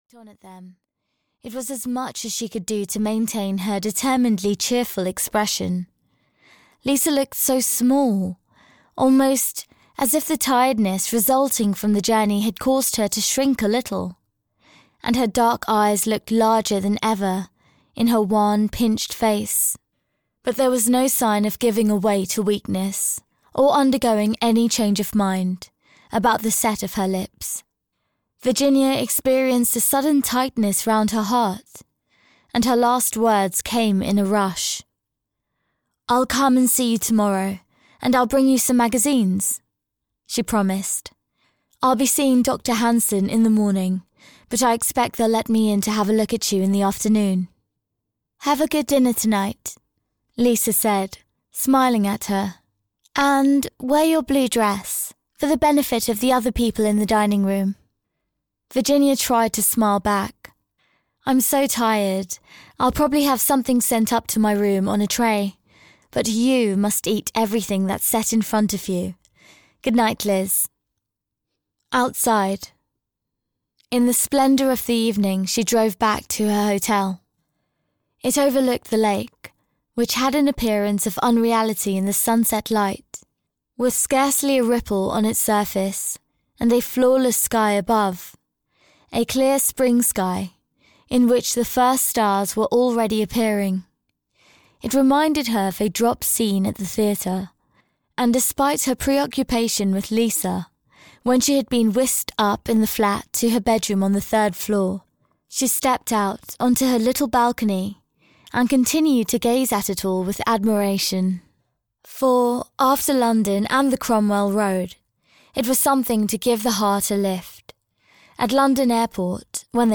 So Dear to my Heart (EN) audiokniha
Ukázka z knihy